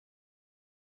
Un fichier audio silencieux (1 seconde) est téléchargeable ci-dessous.
silence_1s.mp3